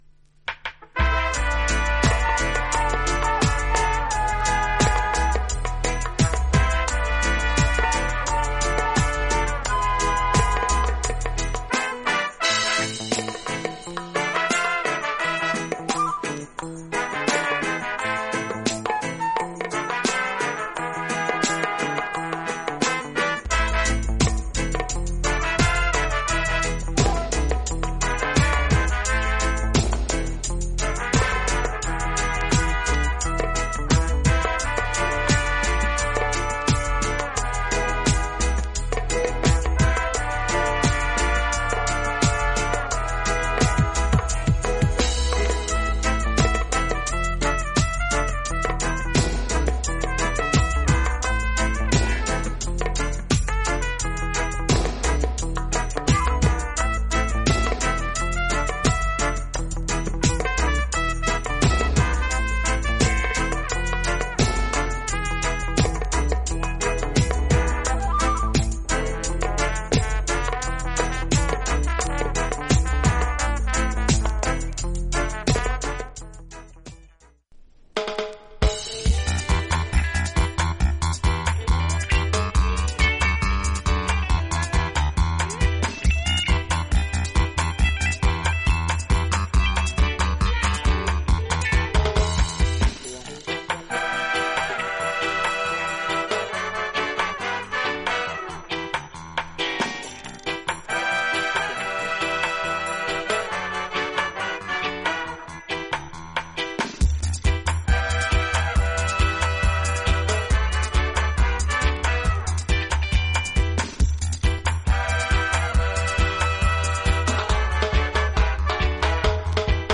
盤面薄いスレが少しありますが音に影響ありません。
実際のレコードからのサンプル↓
類別 雷鬼